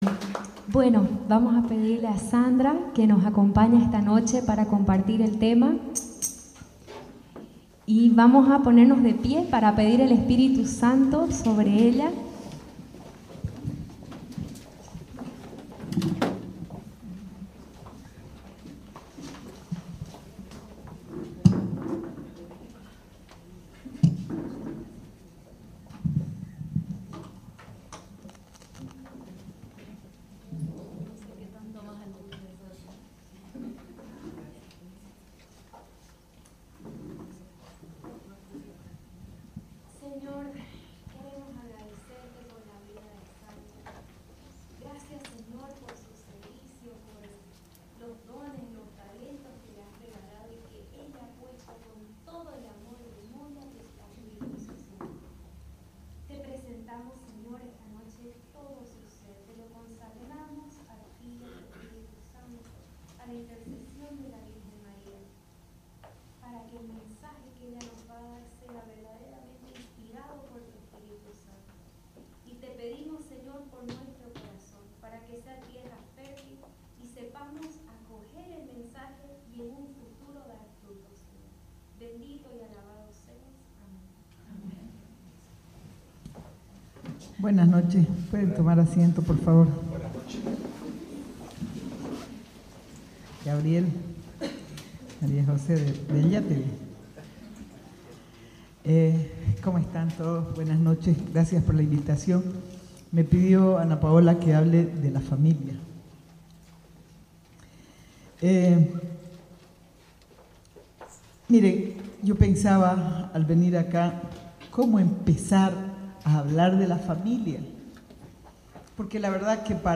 Grupo de Oración del 27 de Junio del 2018